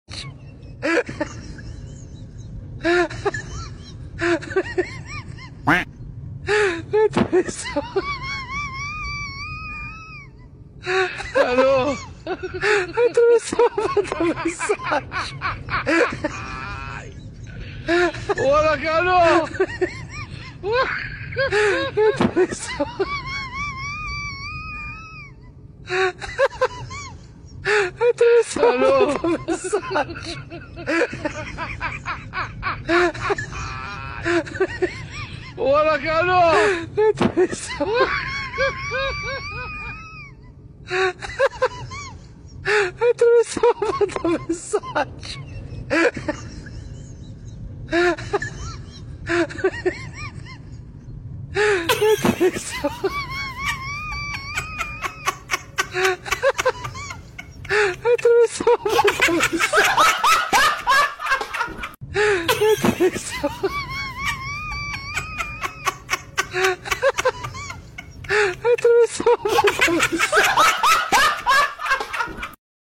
funny game 🎮 chicken 🐔 sound effects free download